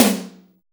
ODD TOM HI.wav